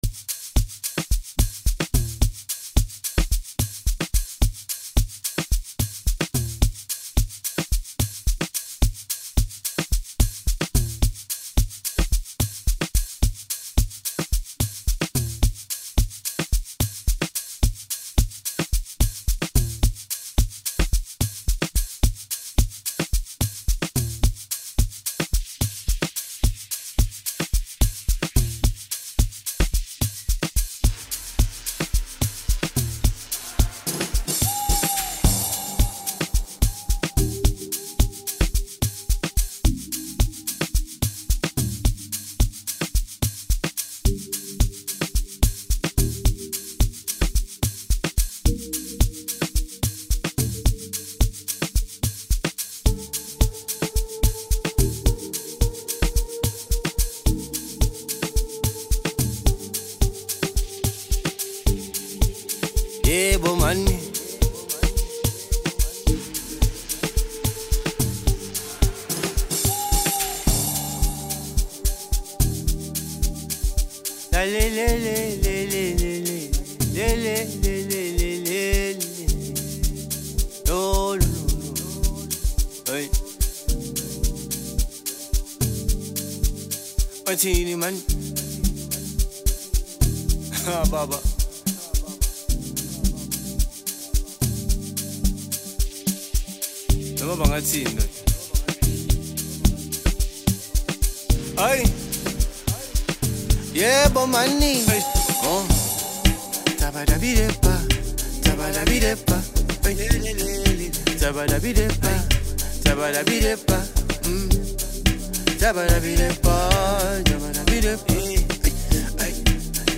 vibrant rhythms
and vocal charm for an unforgettable listener experience.